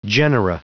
Prononciation du mot genera en anglais (fichier audio)
Prononciation du mot : genera